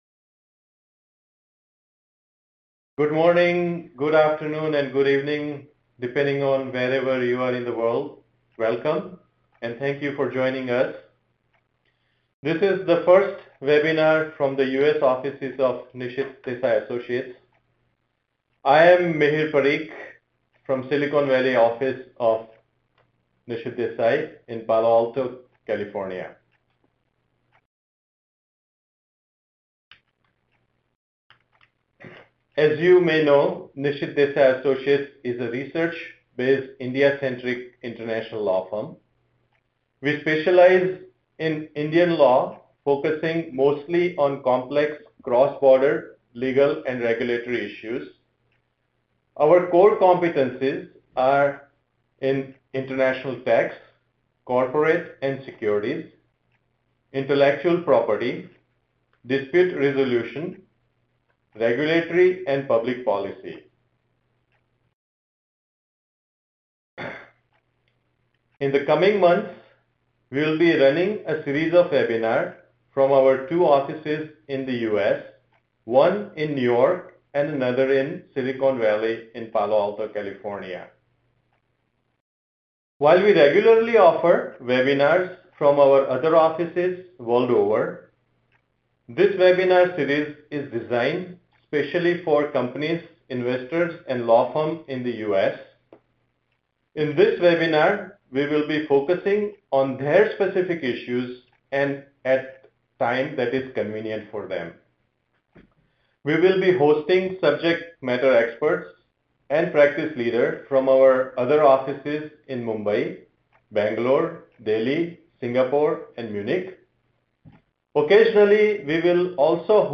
Webinar: How regulation is shaping up e-commerce industry in India